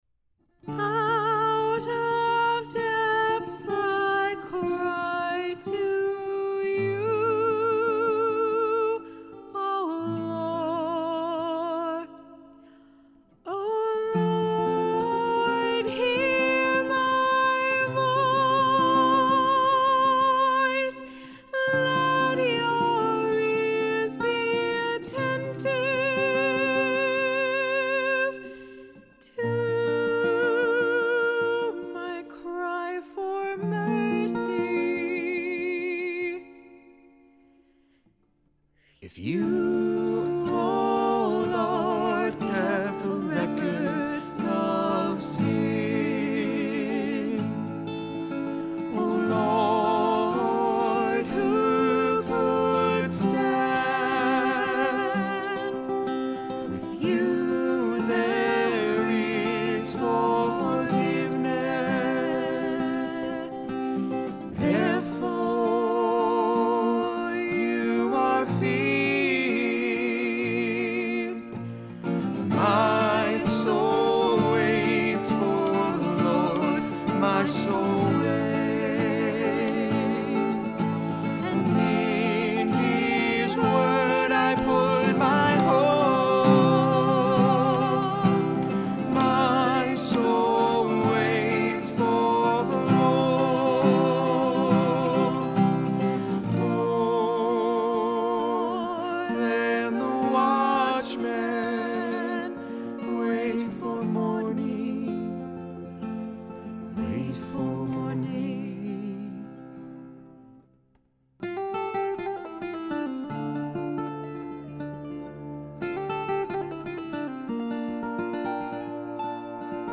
Vocals and Guitar
Drums
Cello